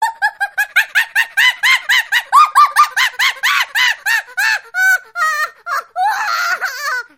جلوه های صوتی
دانلود صدای حیوانات 39 از ساعد نیوز با لینک مستقیم و کیفیت بالا
برچسب: دانلود آهنگ های افکت صوتی انسان و موجودات زنده دانلود آلبوم مجموعه صدای حیوانات مختلف با سبکی خنده دار از افکت صوتی انسان و موجودات زنده